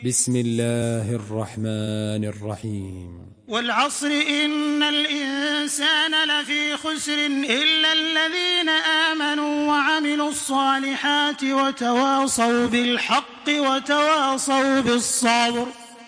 Surah আল-‘আসর MP3 by Makkah Taraweeh 1426 in Hafs An Asim narration.
Murattal